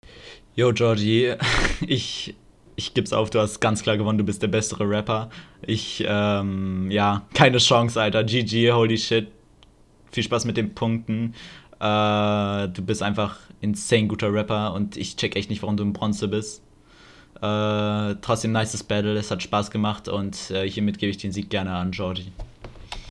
(rauschen beginnt)